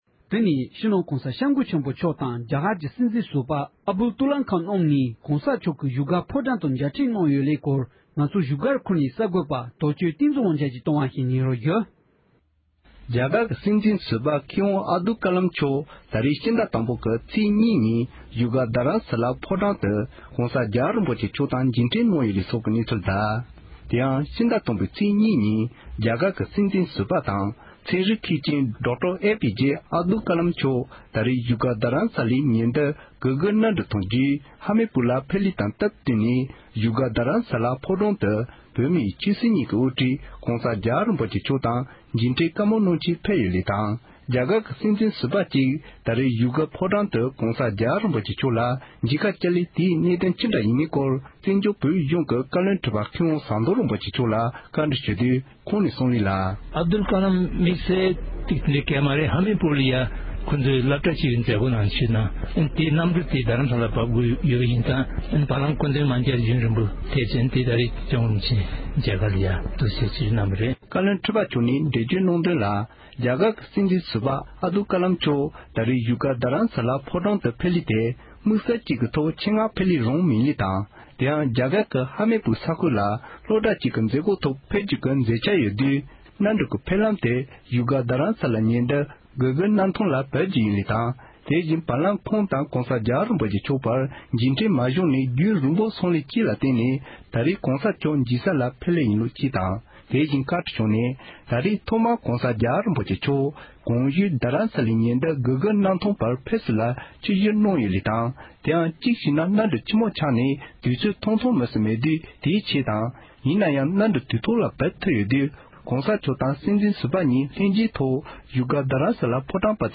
གསར་འགྱུར